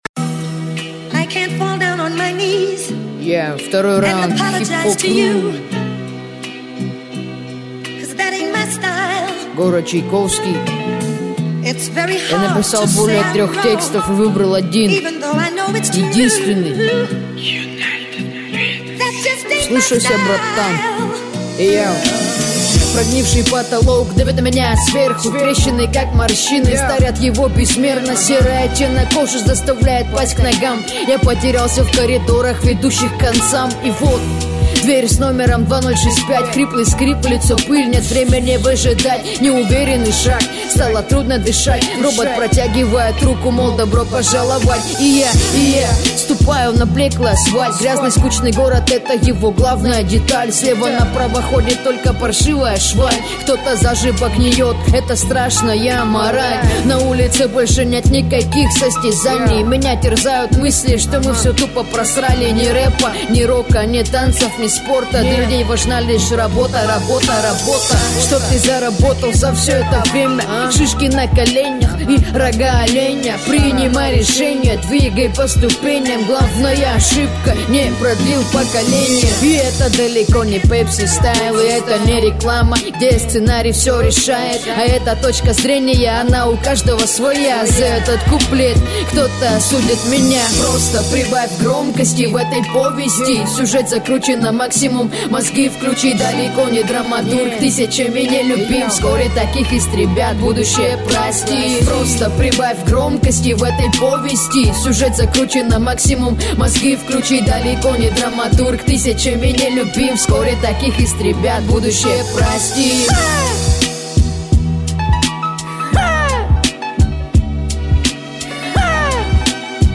написал более трех тестов, ну ты прям мужииик) минус козырный, не плохо читаешь развивайся, но видно чего то не хватает